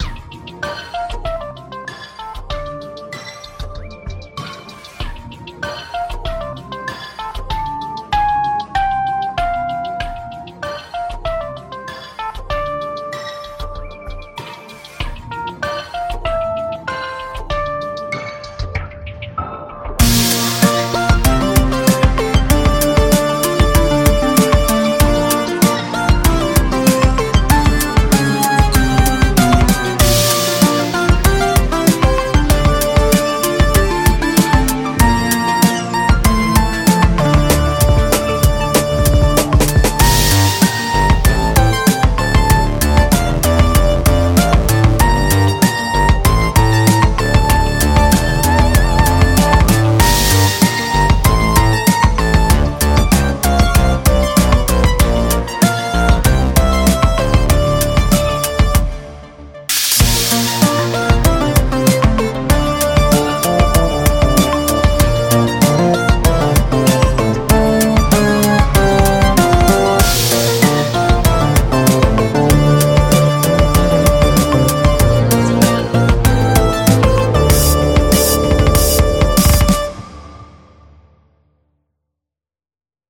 I decided to make some disorganized fun.